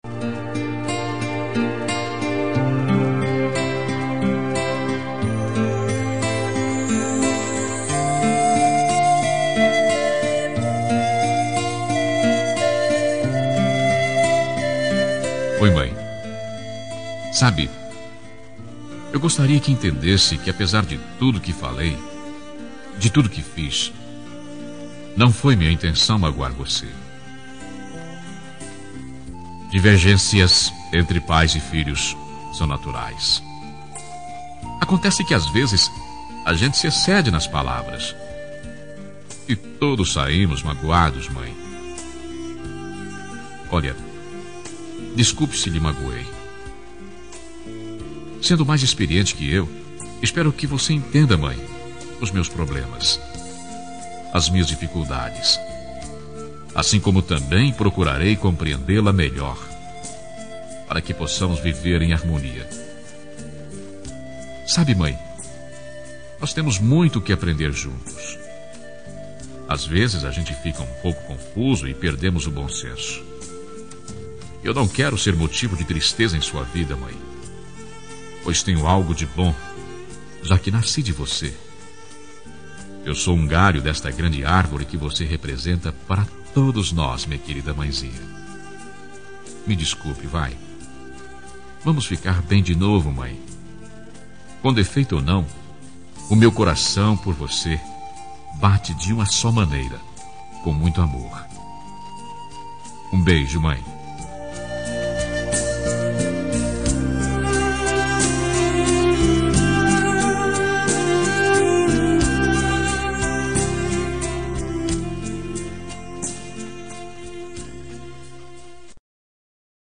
Reconciliação Familiar – Voz Masculina – Cód: 088732 – Mãe